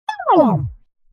fatal_beep.ogg